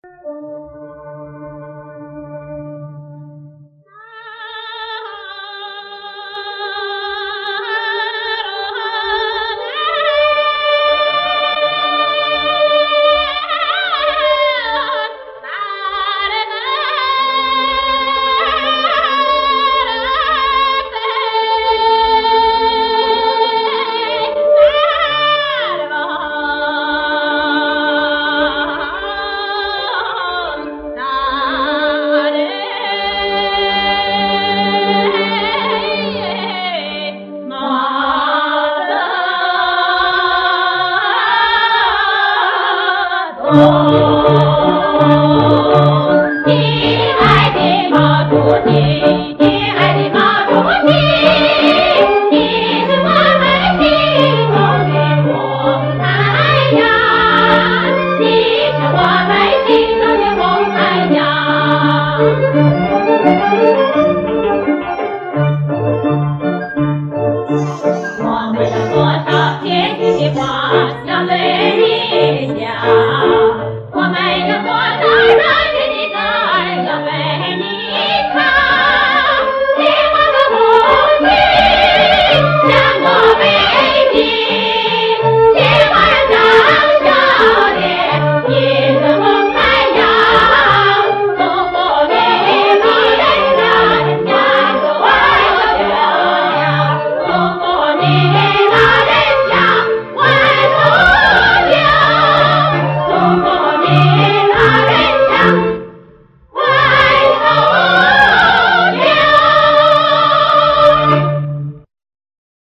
曲调鲜明而昂扬，感情真挚而深厚， 从上世纪六十年代流传至今，一直是人们的厚爱。